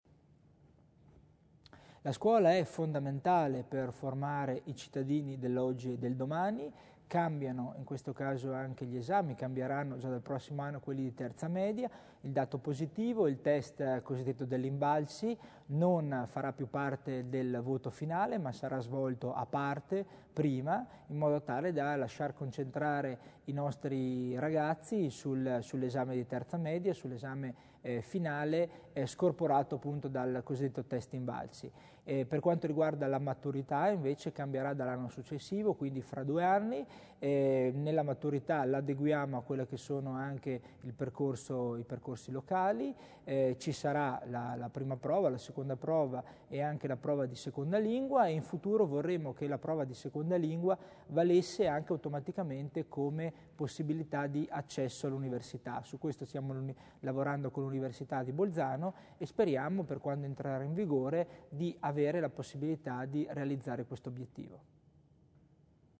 L'Assessore Tommasini illustra le novità in tema di esami scolastici